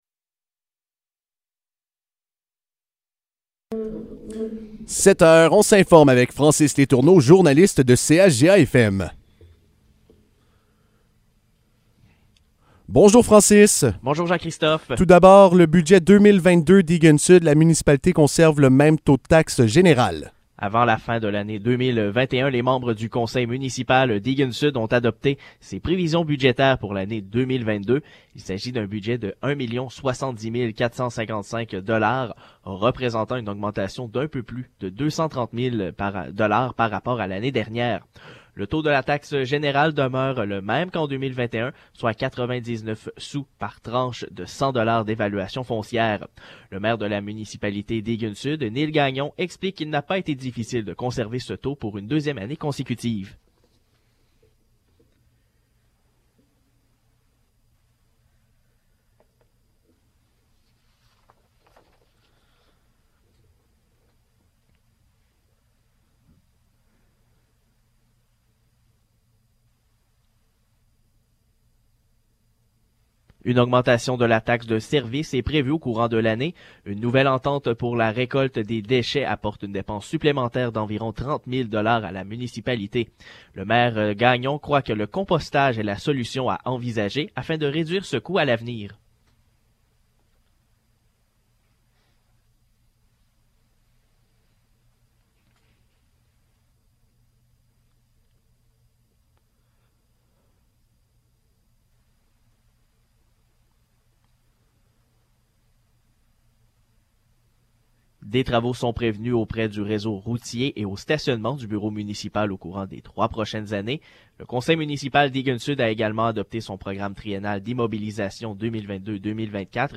Nouvelles locales - 18 janvier 2022 - 7 h